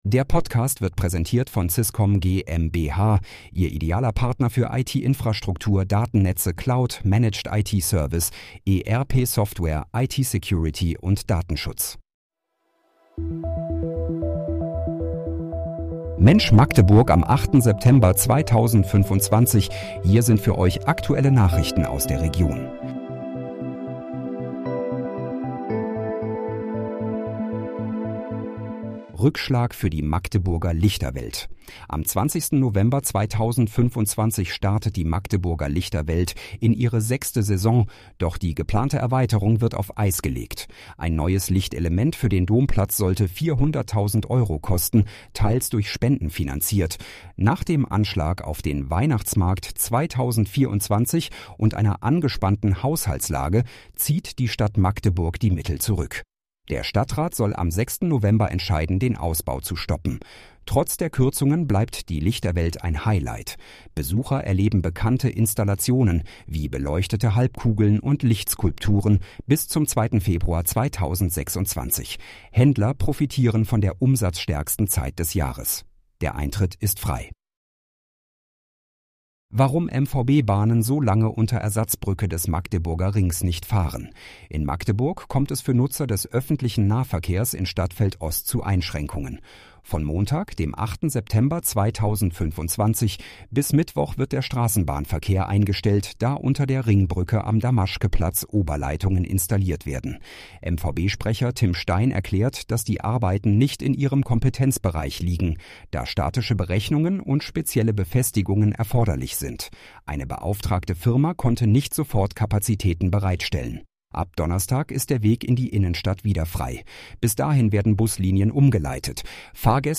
Mensch, Magdeburg: Aktuelle Nachrichten vom 08.09.2025, erstellt mit KI-Unterstützung
Nachrichten